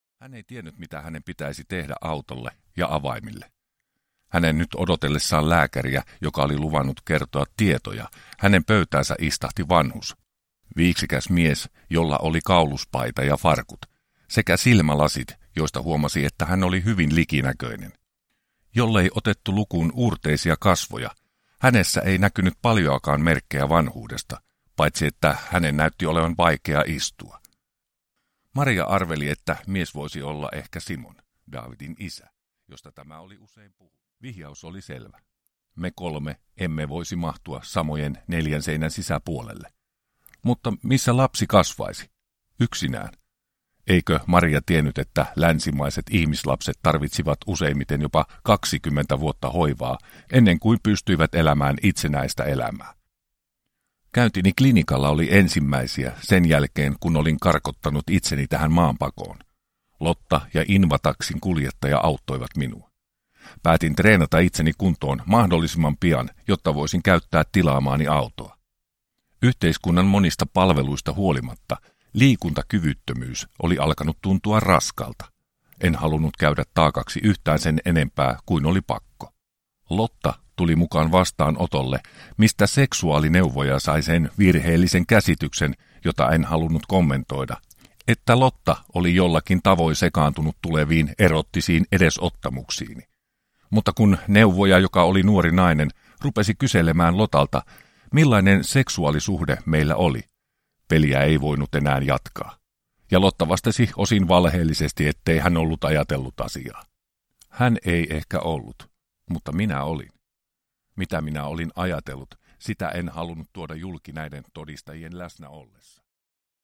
Poika ja isä – Ljudbok – Laddas ner